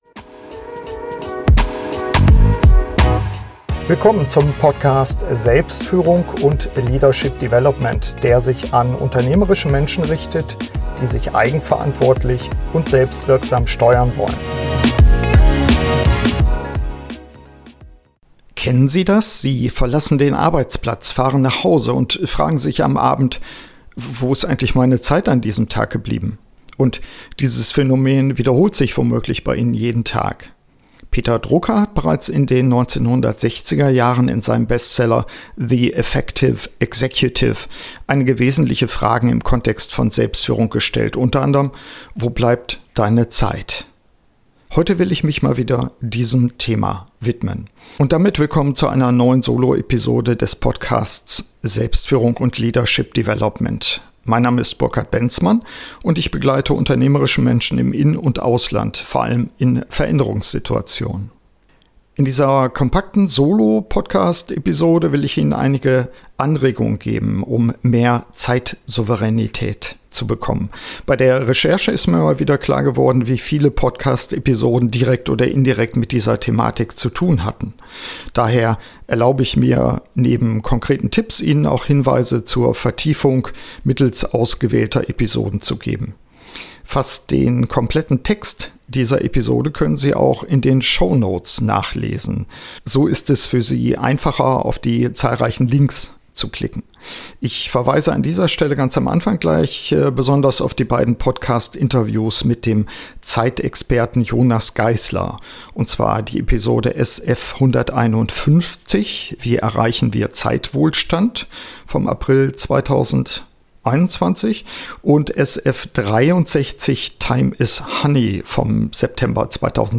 In dieser kompakten Solo-Podcast-Episode gebe ich einige Anregungen, um mehr Zeitsouveränität zu gewinnen.